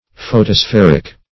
Photospheric \Pho`to*spher"ic\, a. Of or pertaining to the photosphere.